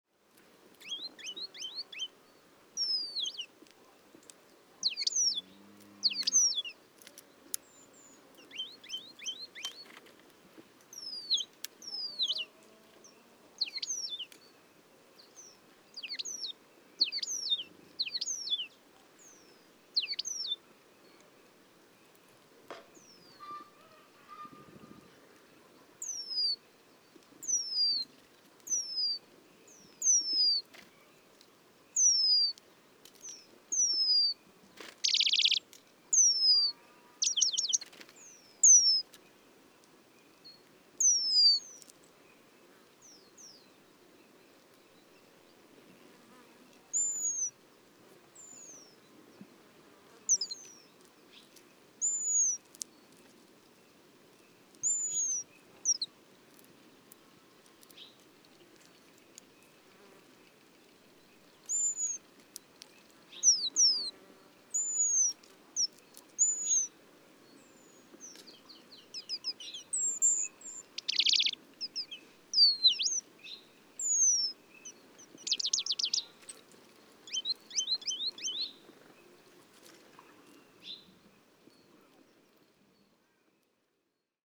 1 White-crowned Peduline Tit Remiz coronatus – WSRS Competition 2011, First Restricted